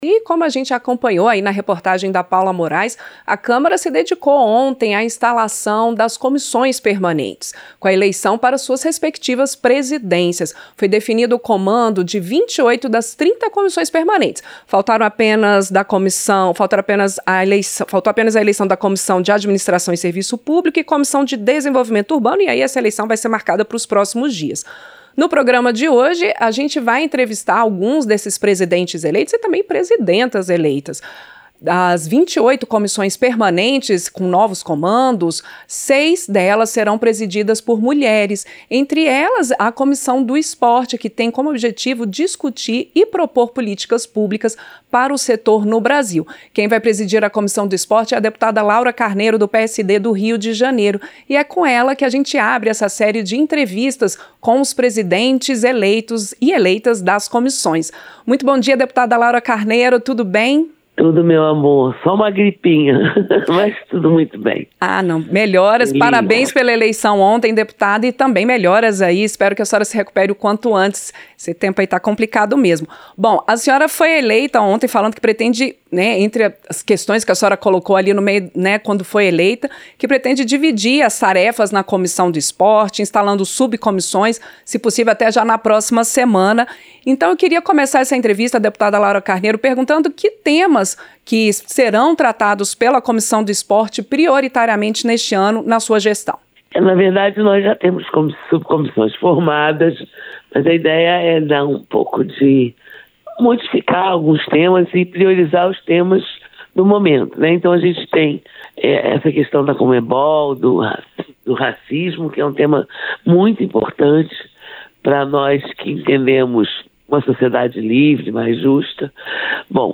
• Entrevista - Dep. Laura Carneiro (PSD-RJ)
Programa ao vivo com reportagens, entrevistas sobre temas relacionados à Câmara dos Deputados, e o que vai ser destaque durante a semana.